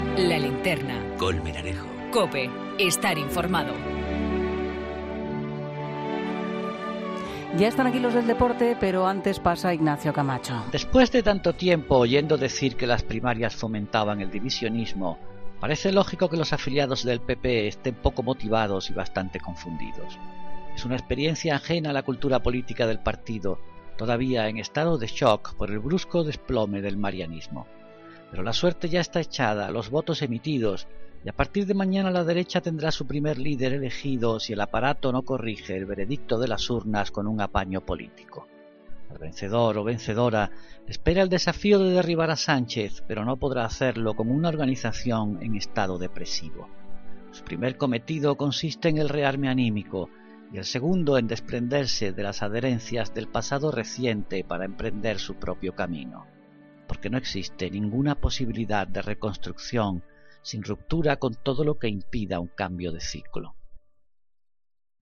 Ignacio Camacho habla en 'La Linterna' de las primarias del Partido Popular.